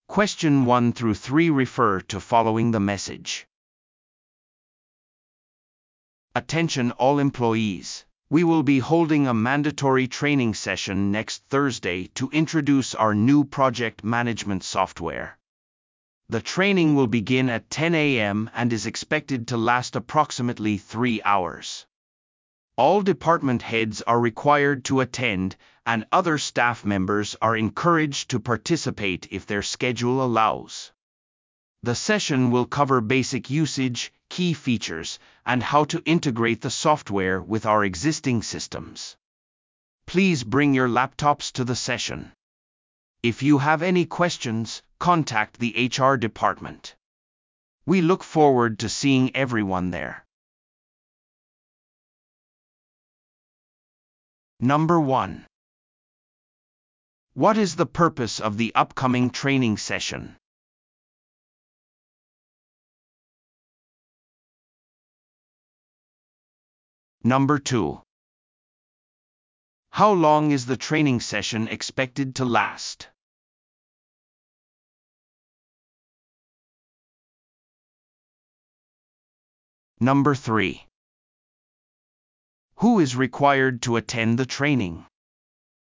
PART４は一人語りの英語音声が流れ、それを聞き取り問題用紙に書かれている設問に回答する形式のリスニング問題。